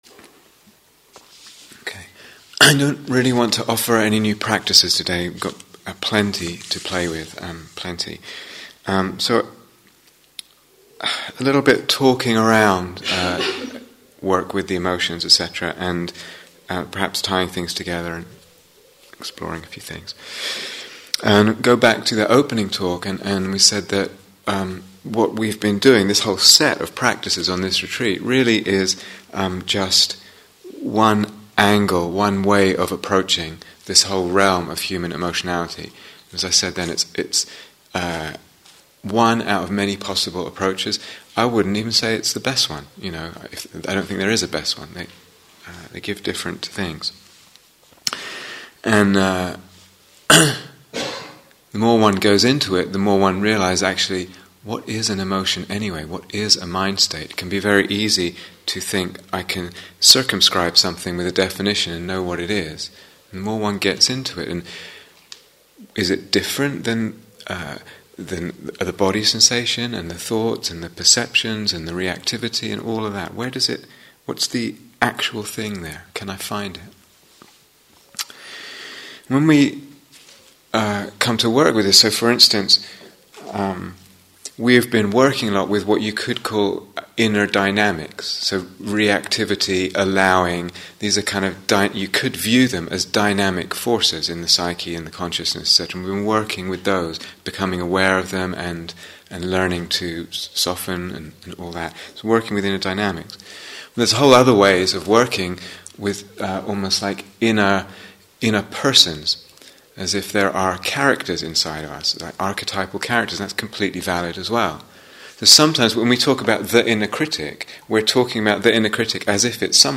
Working with the Emotional Body (Instructions and Guided Meditation: Day Seven)